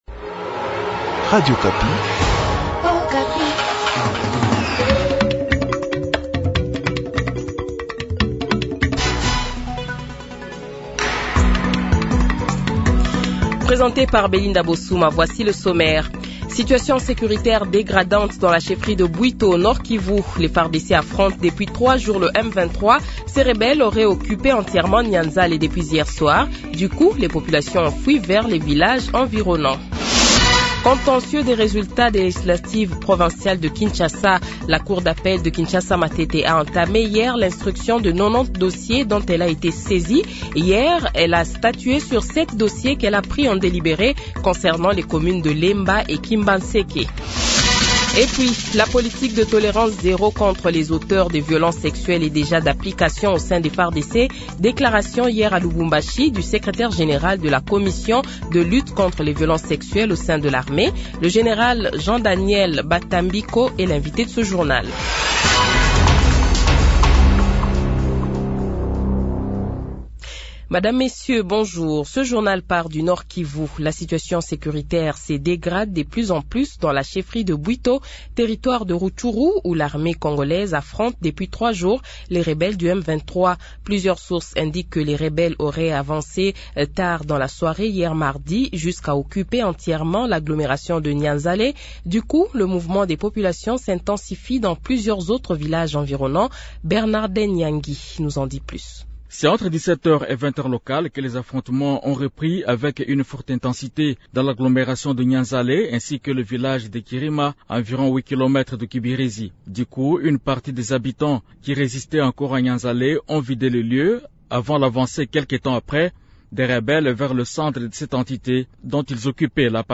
Le Journal de 12h, 06 Mars 2024 :